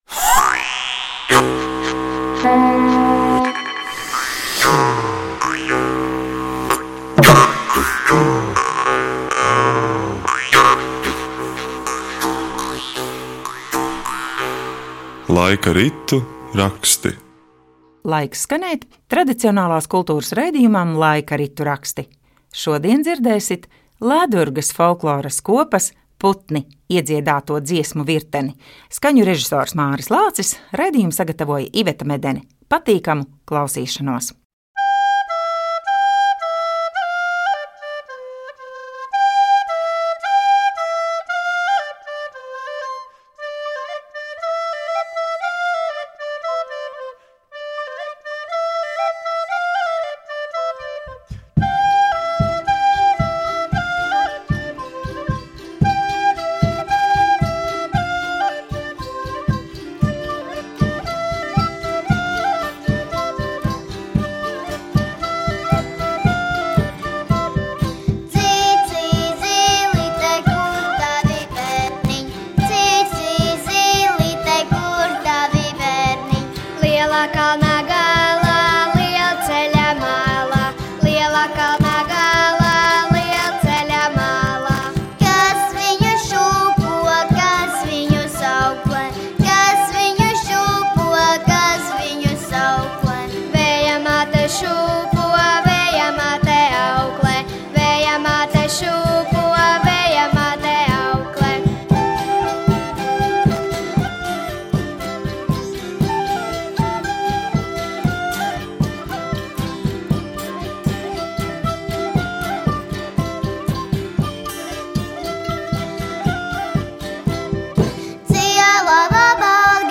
Dziesmas dzied un rotaļās iet Lēdurgas folkloras kopas "Putni" dalībnieki
Latvijas radio 7. studijā